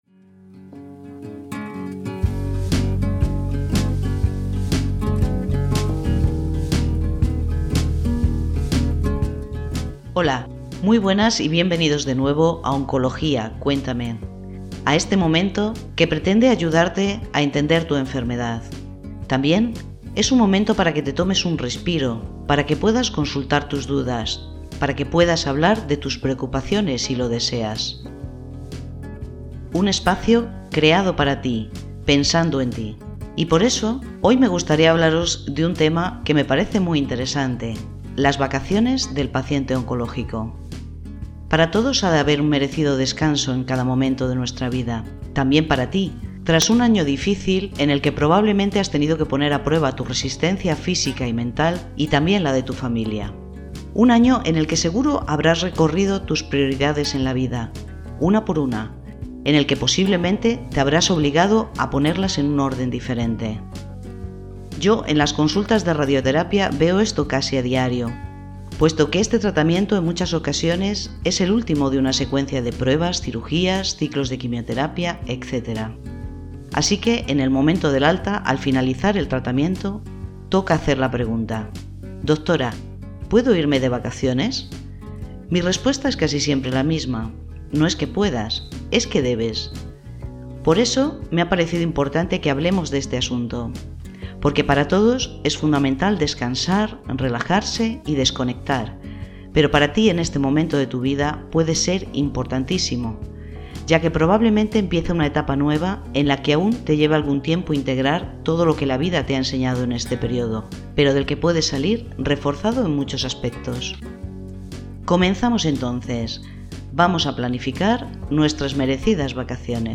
Con su inconfundible estilo radiofónico y su voz envolvente, imprime su particular forma de contar todo aquello que posiblemente se queda en el tintero de las consultas hospitalarias del paciente oncológico, ya sea a través de temas de interés o entrevistas con otros profesionales.